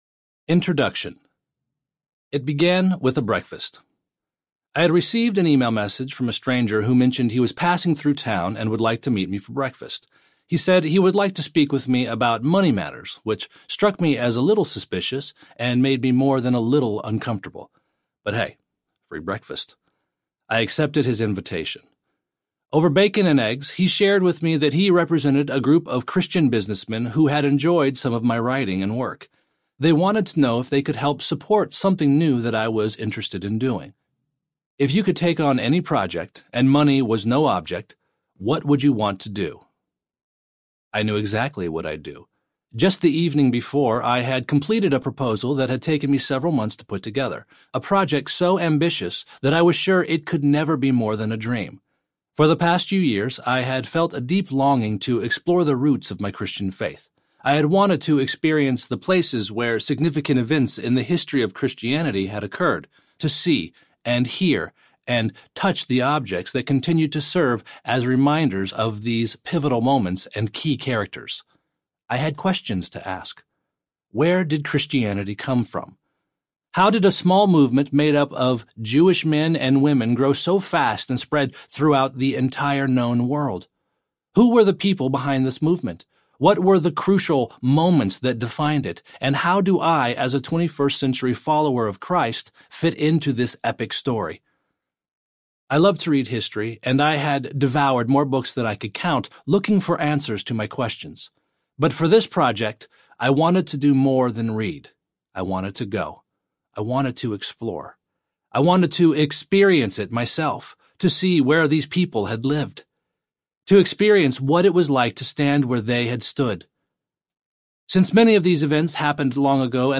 Epic Audiobook
Narrator
5.3 Hrs. – Unabridged